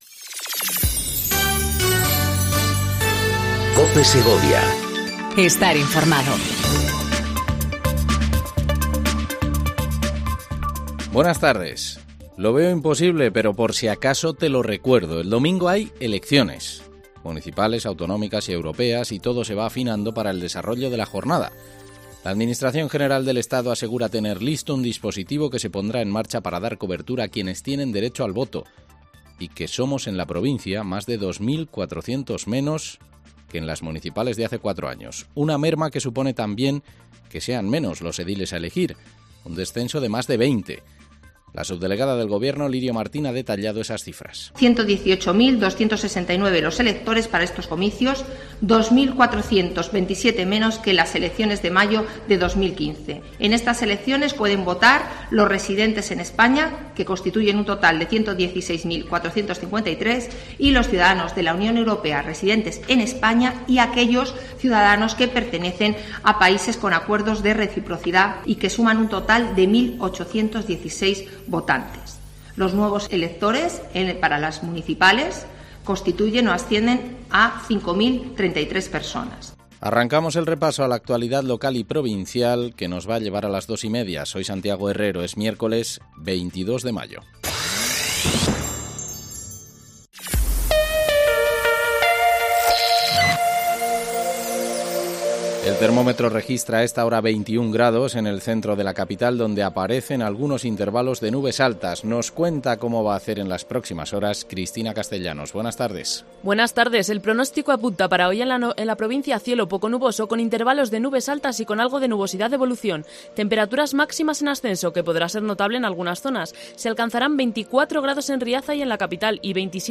INFORMATIVO DEL MEDIODÍA EN COPE SEGOVIA 14:20 DEL 22/05/19